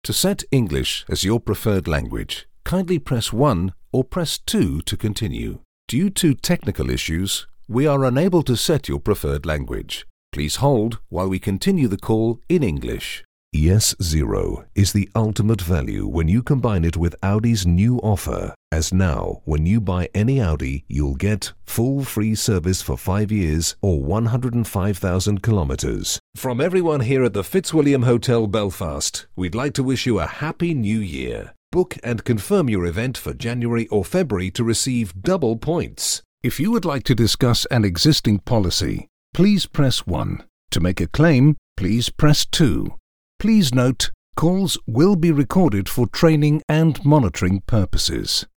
Anglais (britannique)
Aston Spirit microphone
Fully acoustic/sound treated recording environment